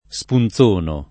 spunzono [ S pun Z1 no ]